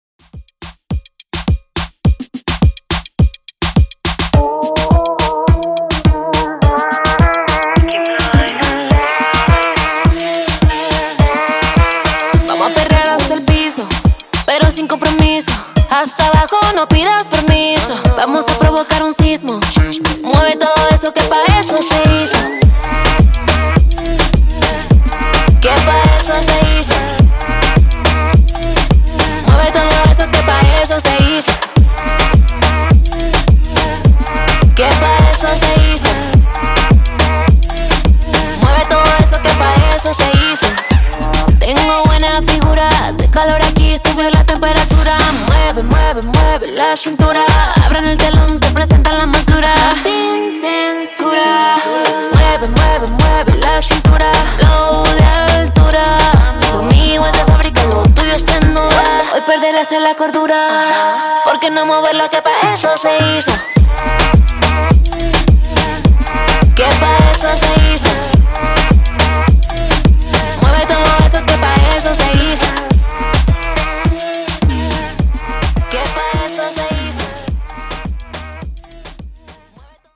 Moombahton)Date Added